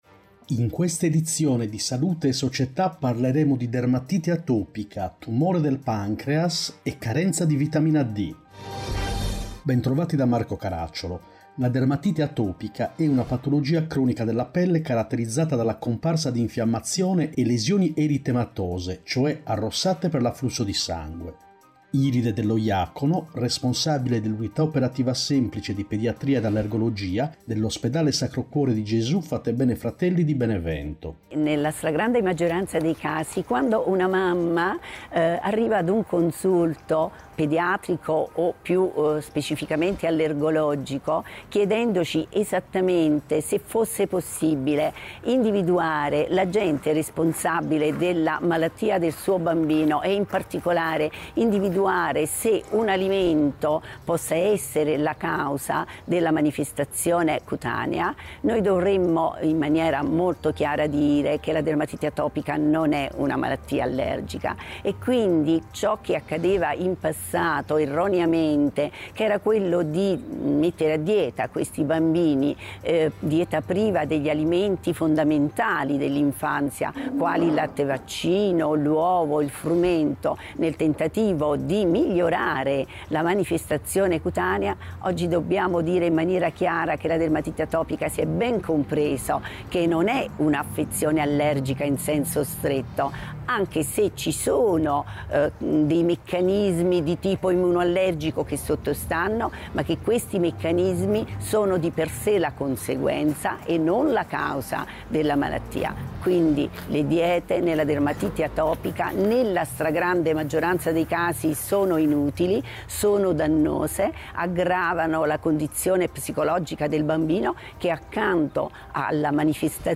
In questa edizione: 1. Dermatite atopica, patologia cronica 2. Tre cose da sapere sul tumore del pancreas 3. Prevenzione e trattamento della carenza di Vitamina D Interviste